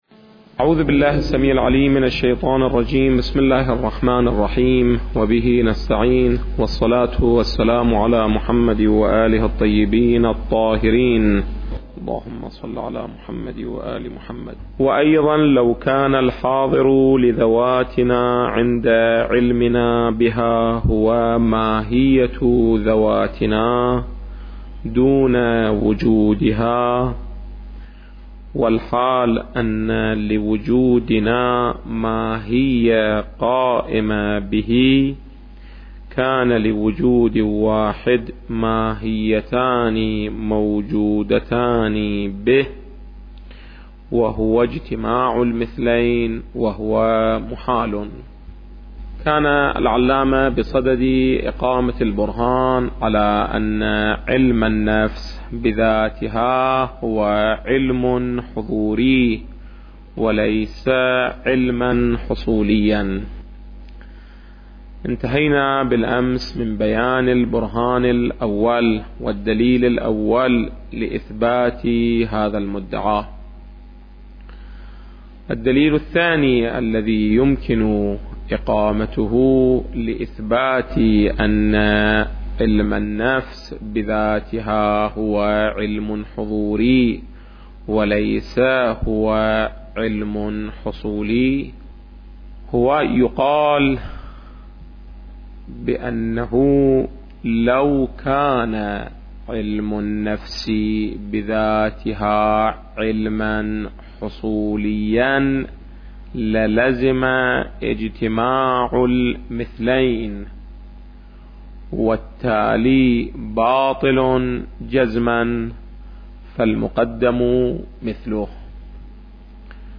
استاد سيد کمال حيدري - نهاية الحکمة | مرجع دانلود دروس صوتی حوزه علمیه دفتر تبلیغات اسلامی قم- بیان